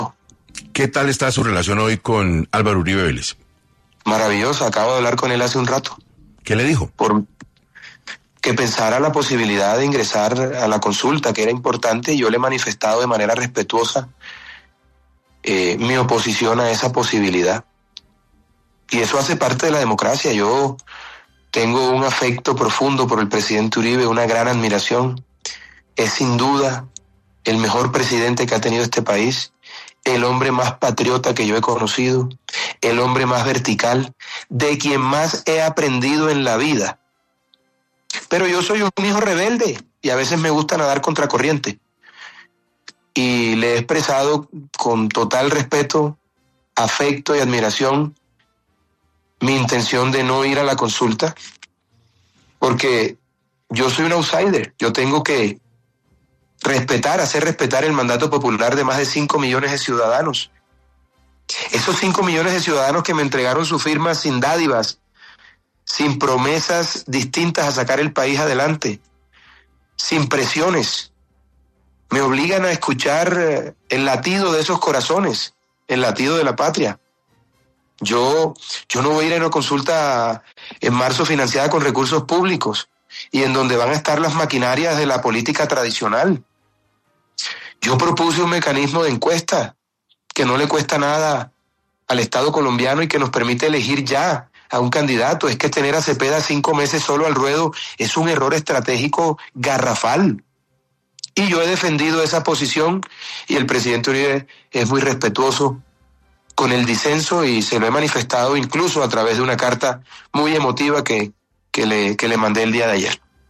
En 6AM de Caracol Radio estuvo Abelardo de la Espriella, quien reveló que el expresidente Álvaro Uribe, le solicitó pensar en la posibilidad de participar en consulta del Centro Democrático 2026